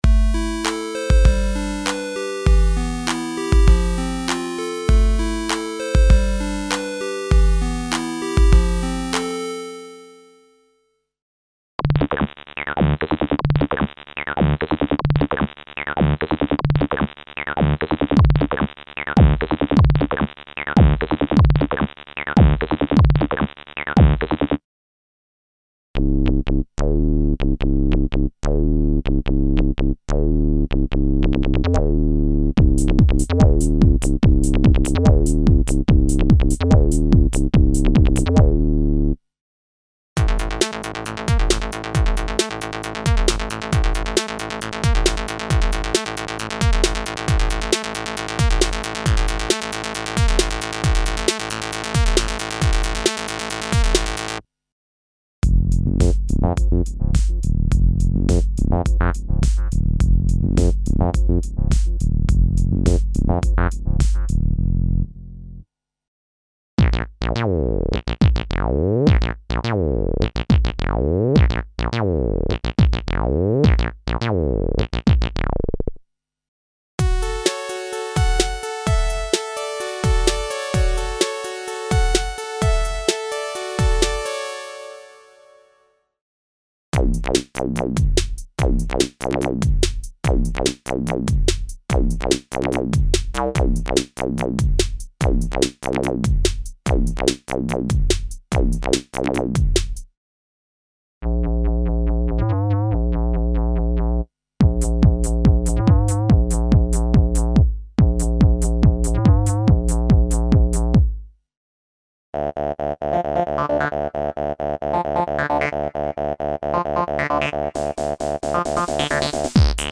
Original collection of saw, square, triangle and sine waveform based sounds for a wide variety of music styles (Techno, House, Trance, Jungle, Rave, Break Beat, Drum´n´Bass, Euro Dance, Hip-Hop, Trip-Hop, Ambient, EBM, Industrial, etc.).
Info: All original K:Works sound programs use internal Kurzweil K2661 ROM samples exclusively, there are no external samples used.